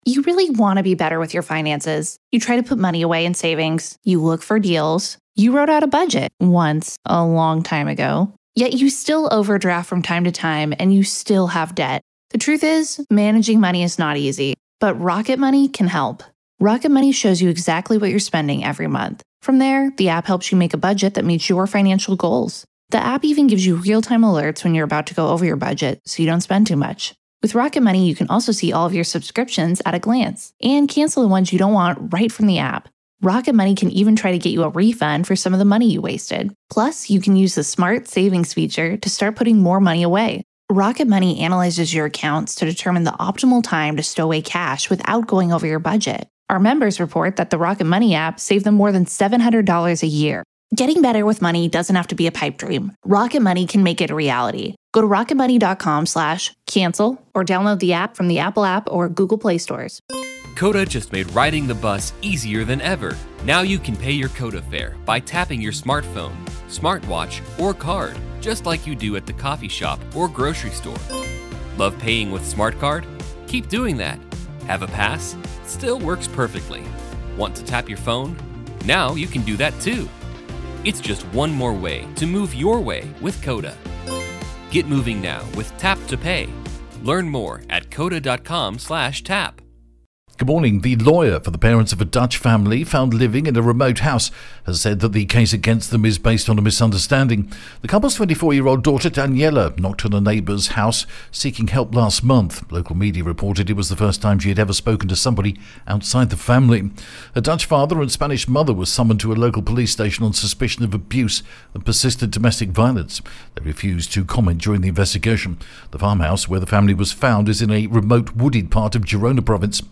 The latest Spanish news headlines in English: 4th April 2023